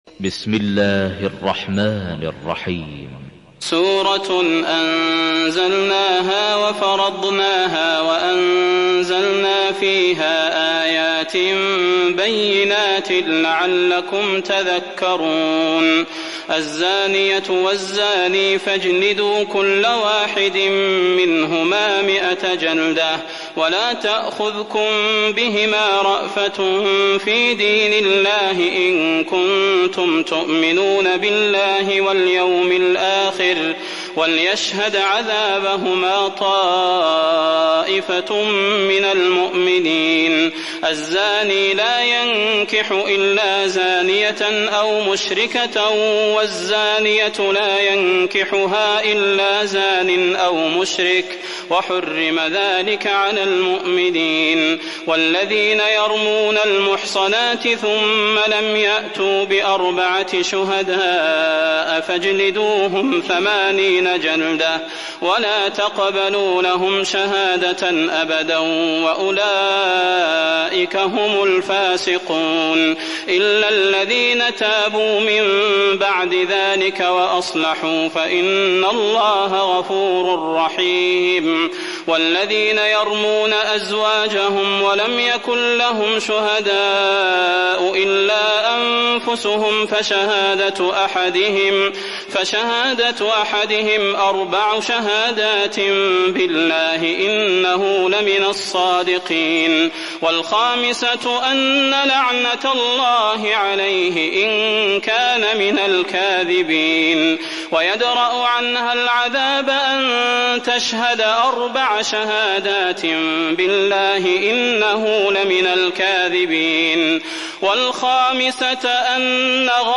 تراويح الليلة السابعة عشر رمضان 1423هـ من سورة النور (1-52) Taraweeh 17 st night Ramadan 1423H from Surah An-Noor > تراويح الحرم النبوي عام 1423 🕌 > التراويح - تلاوات الحرمين